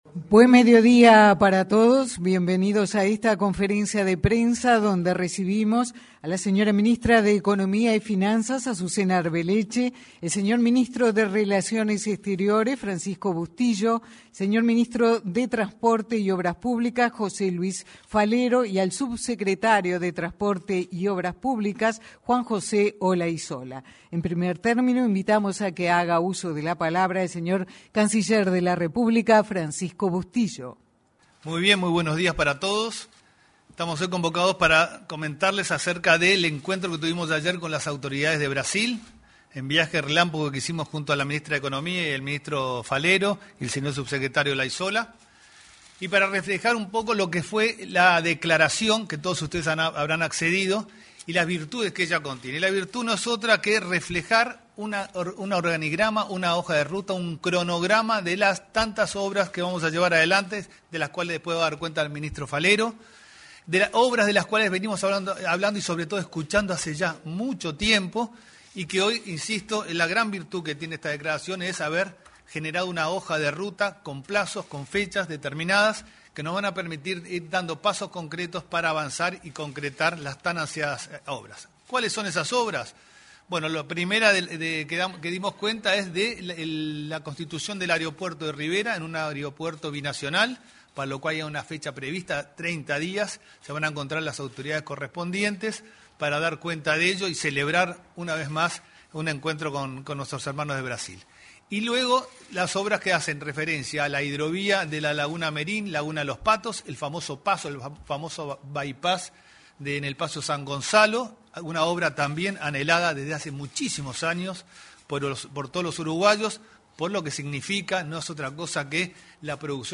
Conferencia de prensa de los ministros de Relaciones Exteriores, Transporte y Economía
Conferencia de prensa de los ministros de Relaciones Exteriores, Transporte y Economía 08/03/2023 Compartir Facebook Twitter Copiar enlace WhatsApp LinkedIn Los ministros de Relaciones Exteriores, Francisco Bustillo; Transporte y Obras Públicas, José Luis Falero, y Economía y Finanzas, Azucena Arbeleche, se expresaron en una conferencia de prensa acerca de los acuerdos entre Uruguay y Brasil por obras de infraestructura en zonas de frontera.